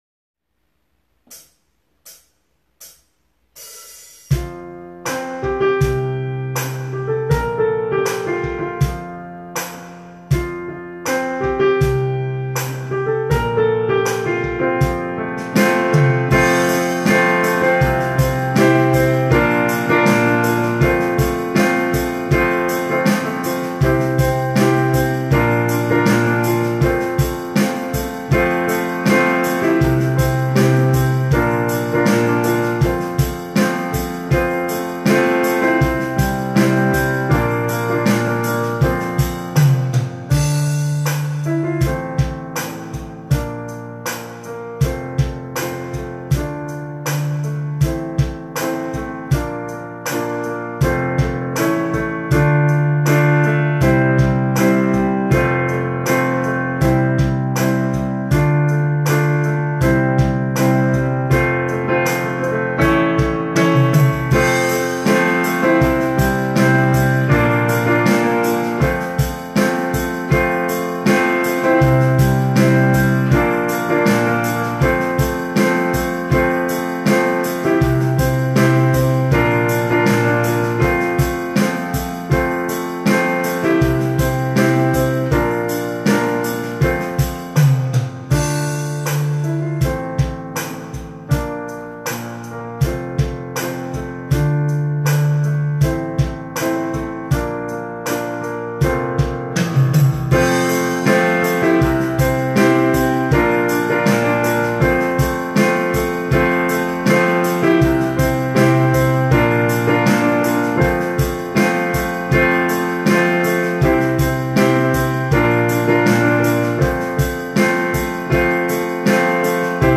A la fin de la journée, les familles, les professeurs principaux, les chefs d'établissement, les membres de la communauté éducative ont été invités dans le hall du collège pour écouter et applaudir les 7 classes de 6èmes qui ont interprété ces deux chansons en choeur.